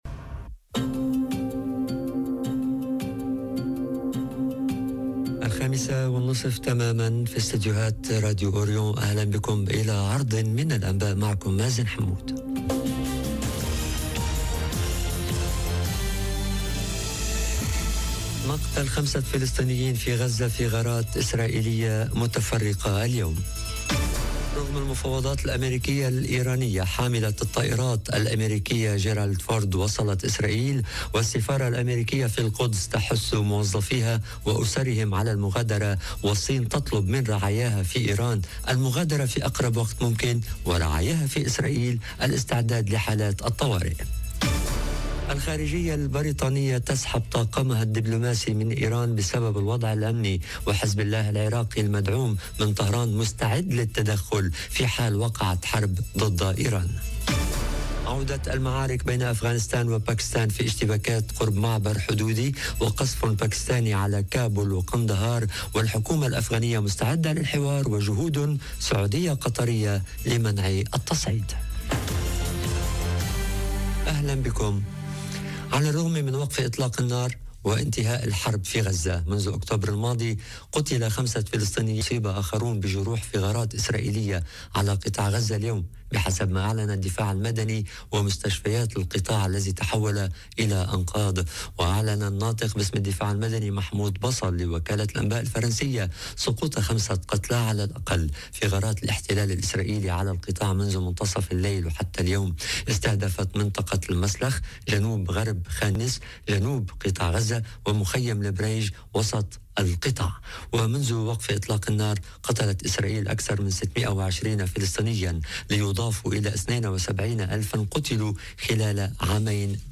نشرة المساء.. قتلى بغزة وحاملة طائرات أمريكية بإسرائيل - Radio ORIENT، إذاعة الشرق من باريس